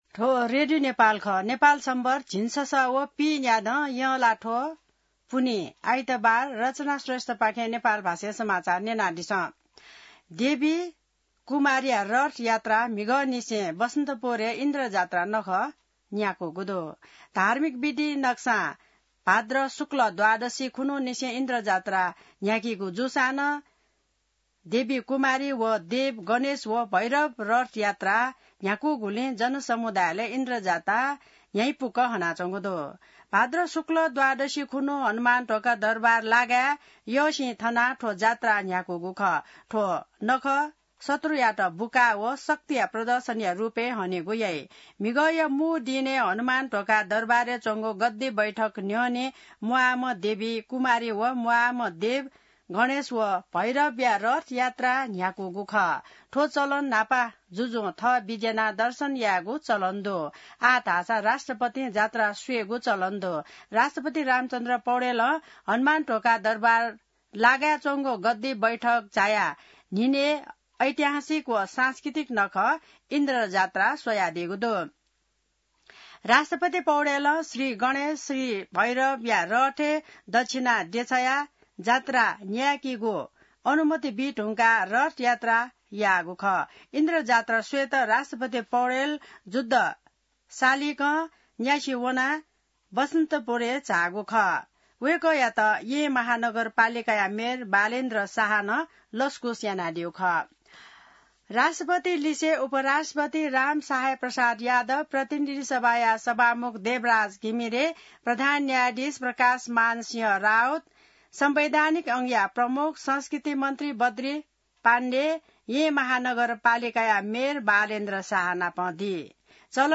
नेपाल भाषामा समाचार : २२ भदौ , २०८२